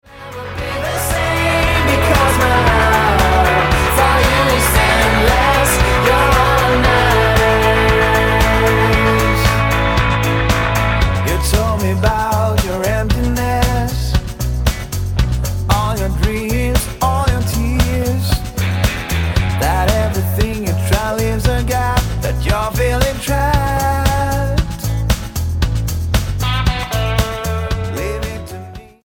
A pop rock band from Norway
Style: Pop